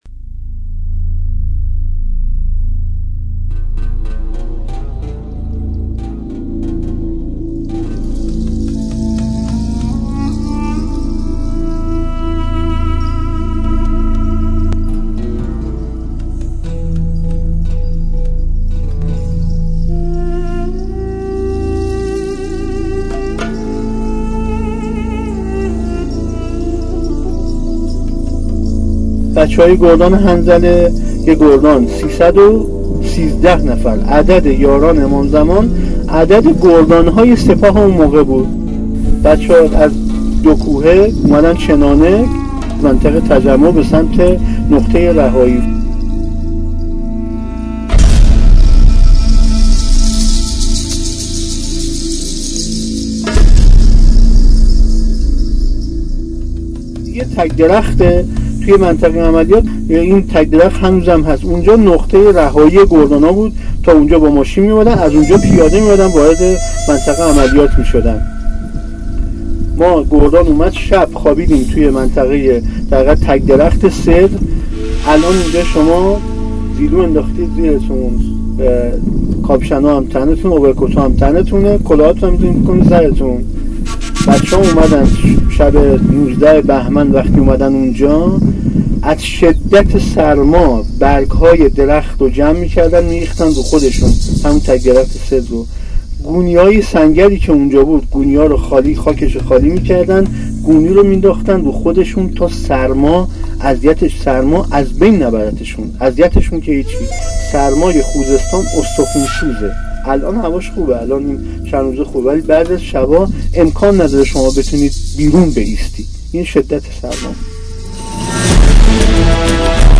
کمیل/ روایت یادمان